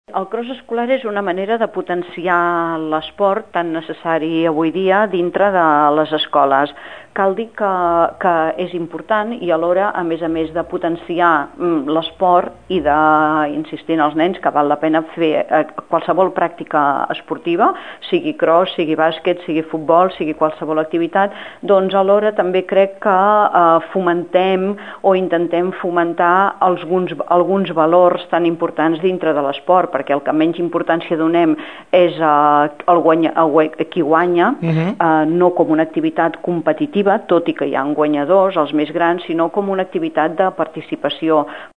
M.Àngels Cayró, regidora d’ensenyament de l’Ajuntament de Tordera, ens recorda la importància d’aquesta activitat per al municipi.